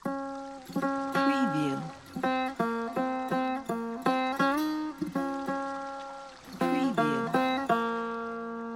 لوپ ملودی سه تار | دانلود سمپل سه تار
لوپ ملودی سه تار | بیش از 300 ملودی سه تار اجرا شده با ساز لایو و کیفیت استودیویی در گام ها و سرعت های مختلف اماده استفاده در پروژه تنظیم